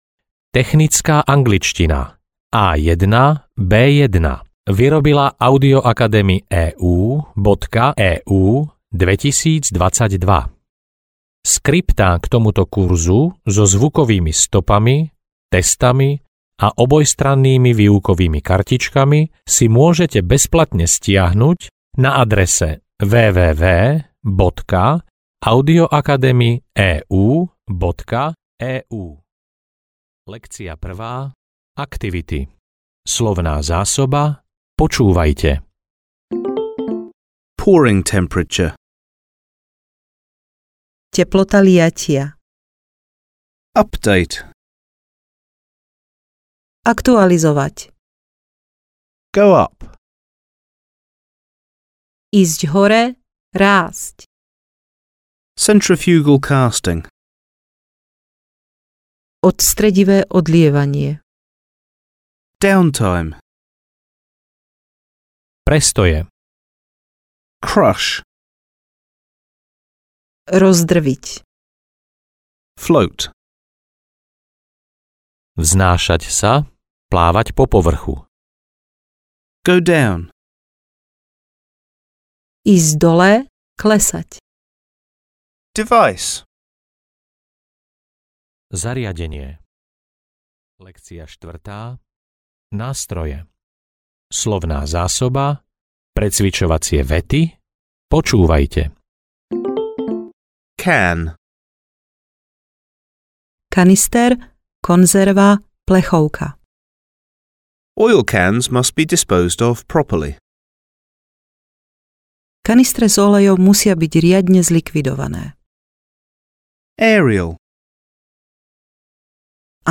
Technická angličtina A1 – B1 audiokniha
Ukázka z knihy